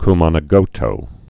(k-mänä-gōtō)